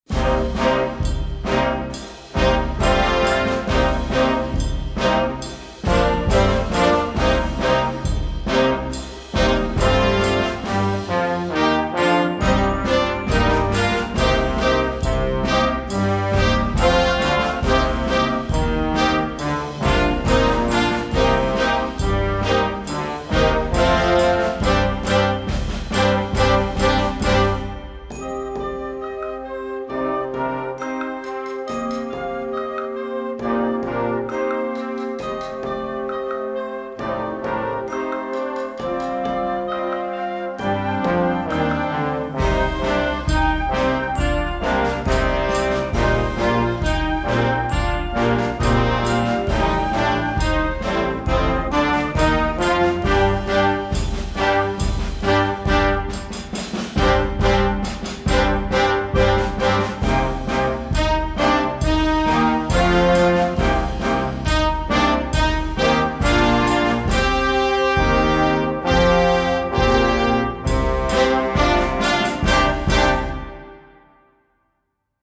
Concert March